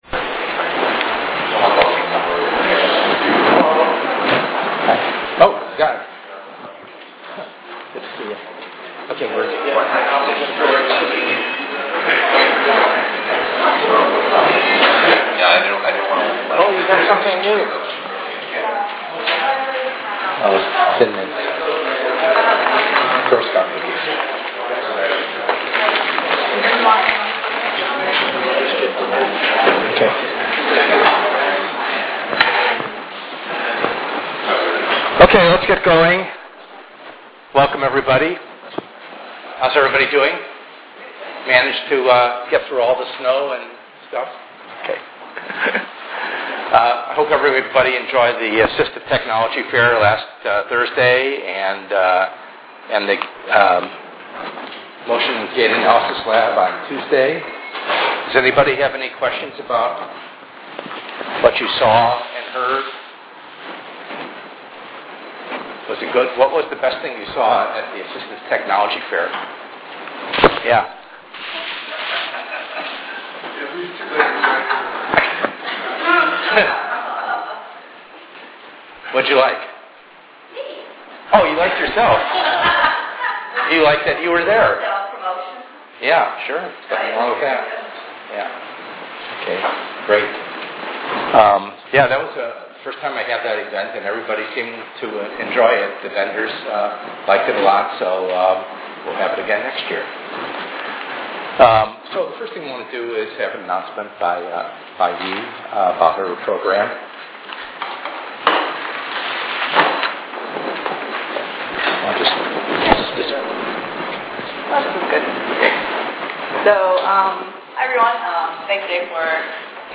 ENGR110/210: Perspectives in Assistive Technology - Lecture 08b